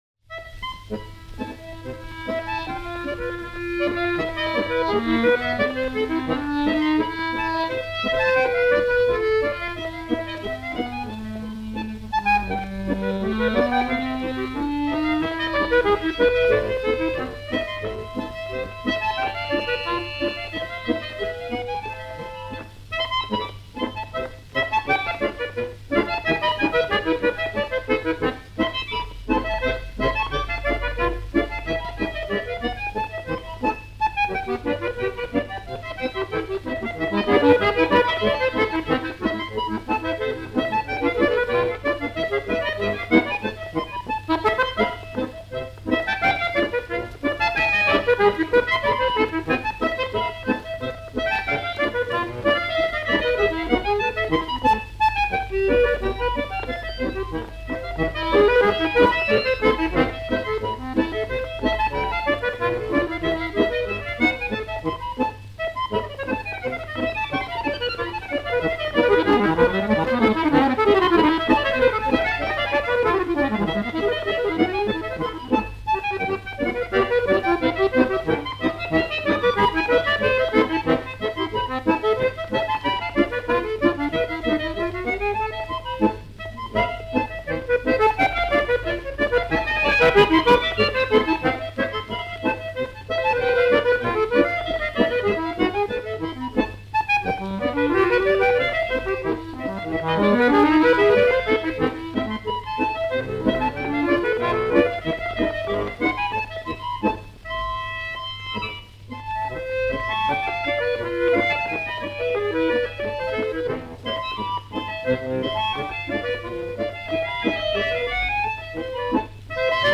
Описание: Баянные танцевальные наигрыши советской деревни.
дуэт баянов